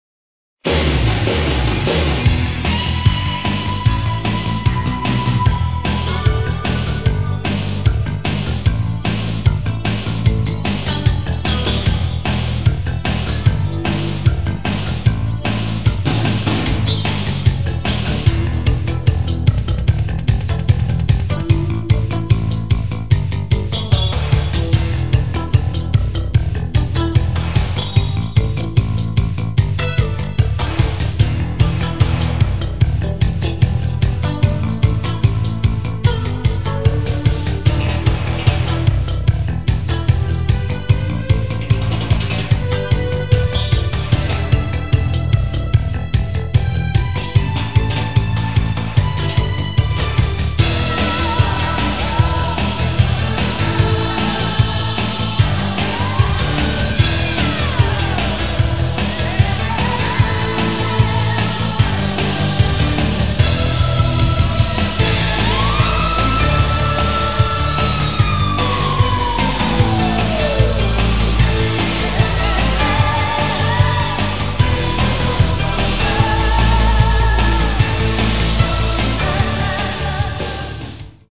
Buoyant, dramatic and heartfelt